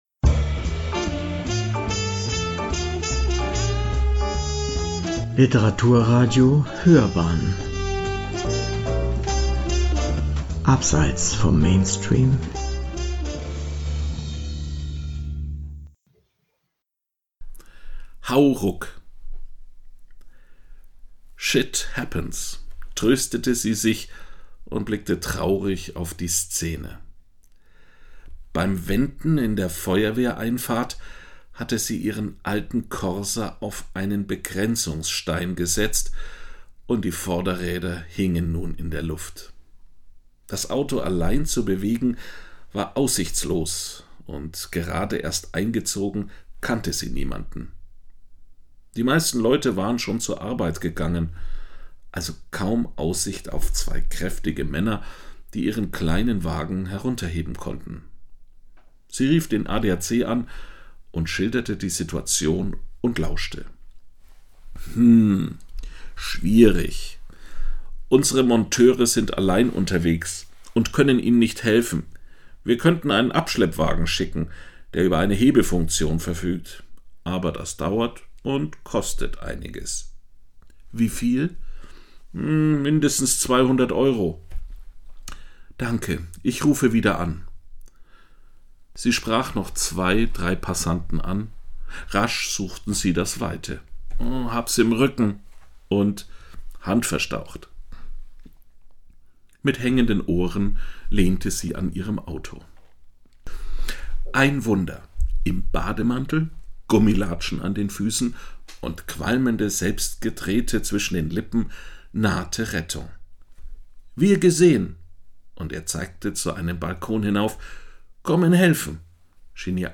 Erleben Sie ein ganzes Jahr lang, jeden Dienstag eine Geschichte von der Piazza an der Bo.